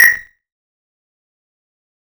Blip.wav